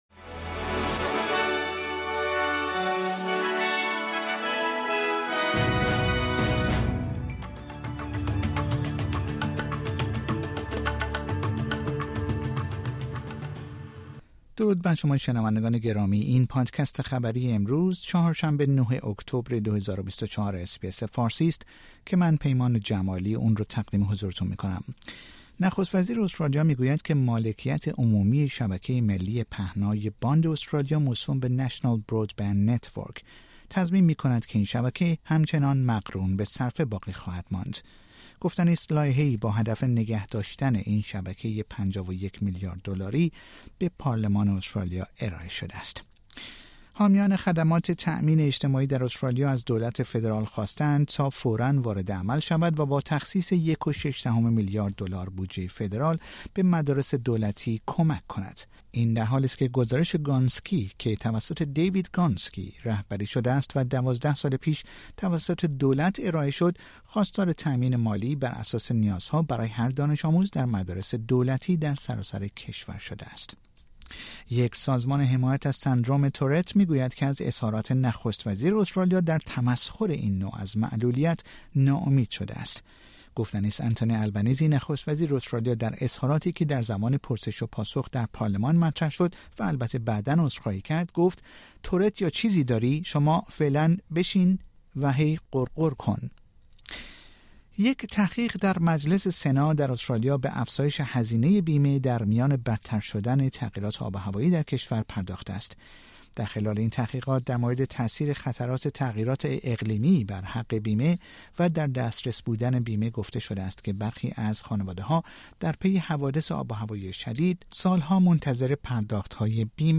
در این پادکست خبری مهمترین اخبار استرالیا در روز چهارشنبه ۹ اکتبر ۲۰۲۴ ارائه شده است.